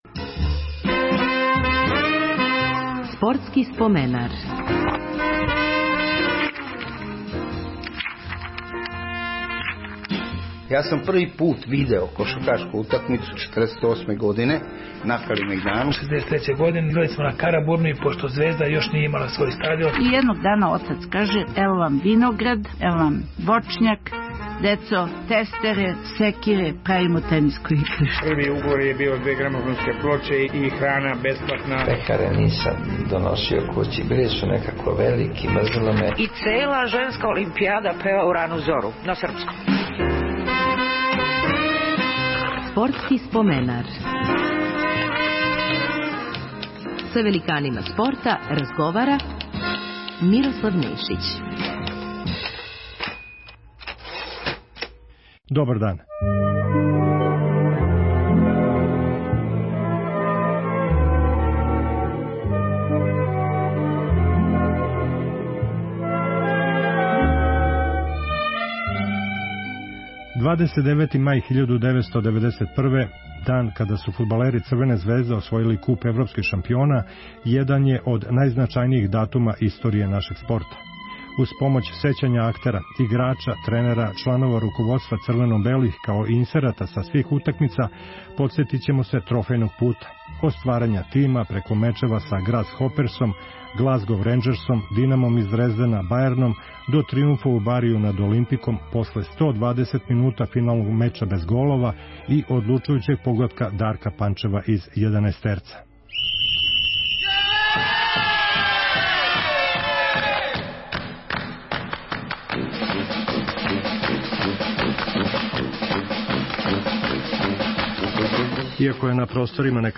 О стварању шампионског тима, као и путу ка највећем успеху нашег фудбала говориће: капитен Стеван Стојановић, Дарко Панчев, Роберт Просинечки, Дејан Савићевић, тренер Љупко Петровић, директор Драган Џајић... Њихова сећања на утакмице са Грасхоперсом, Ренџерсом, Динамом из Дрездена, Бајерном и Олимпиком биће илустрована и инсертима преноса.